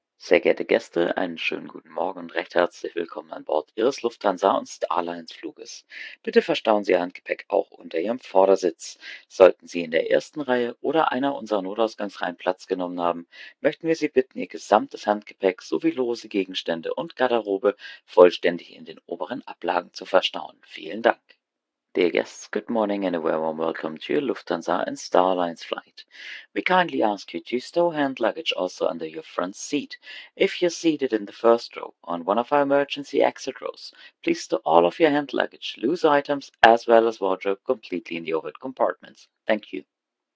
BoardingWelcome[Morning].ogg